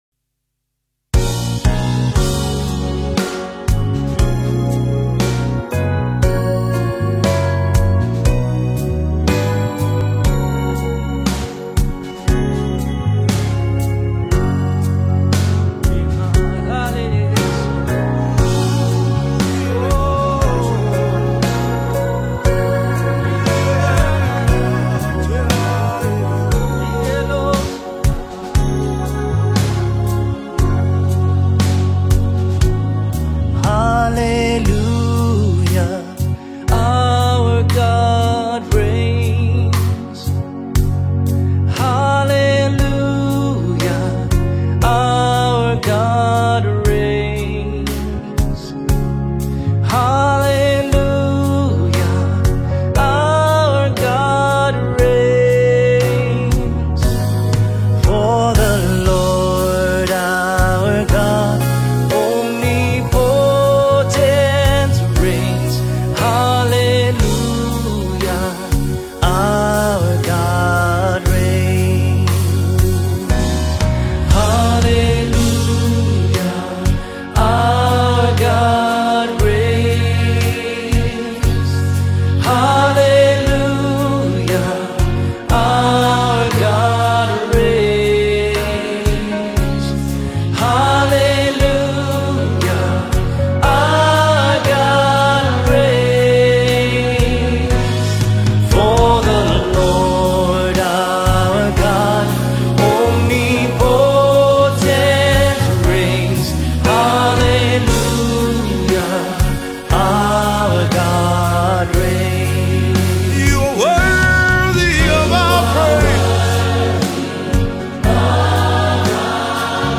March 19, 2025 Publisher 01 Gospel 0